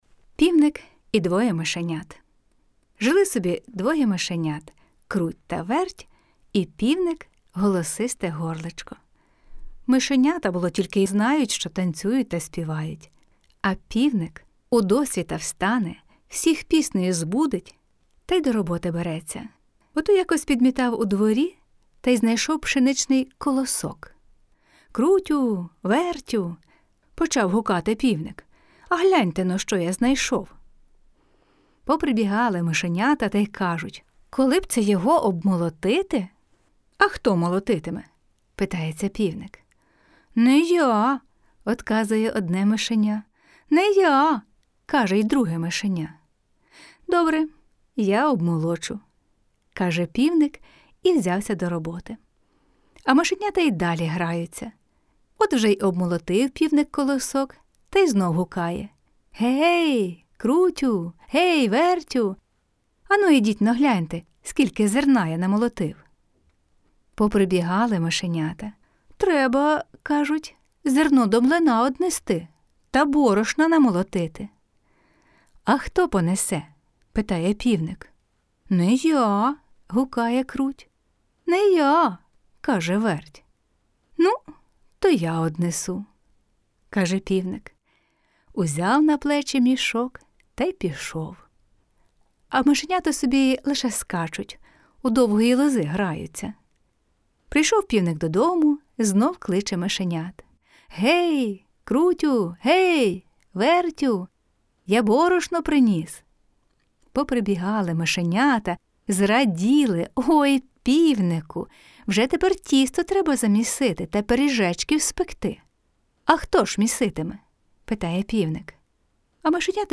Казка "Півник і двоє мишенят" - слухати онлайн